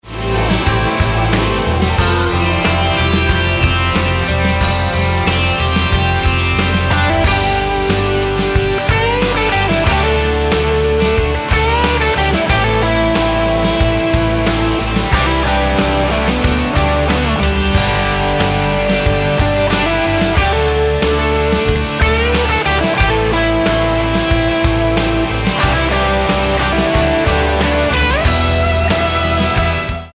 Tonart:G mit Chor